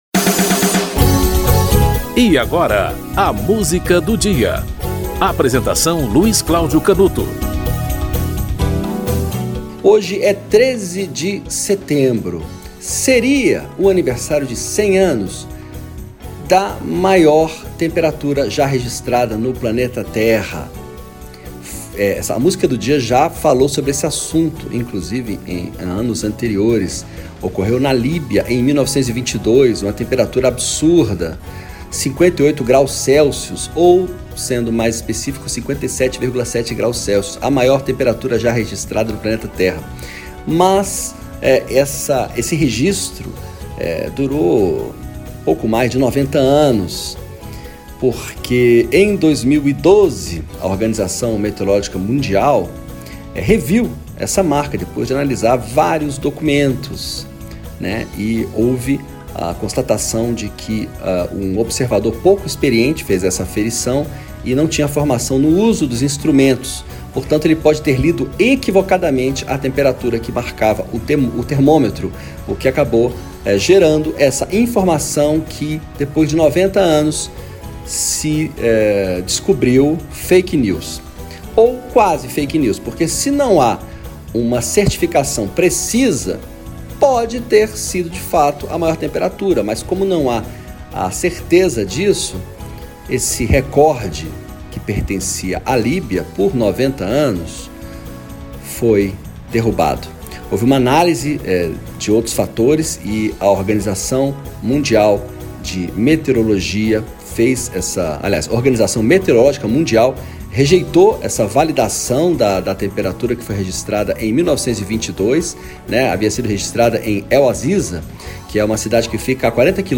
Carlos Galhardo - Allah-La Ô (Haroldo Lobo e Antonio Nássara)
O programa apresenta, diariamente, uma música para "ilustrar" um fato histórico ou curioso que ocorreu naquele dia ao longo da História.